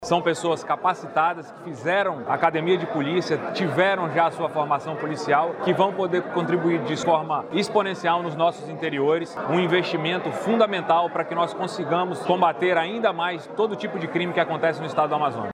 O delegado-geral da Polícia Civil do Amazonas, Bruno Fraga, destaca a capacitação dos aprovados.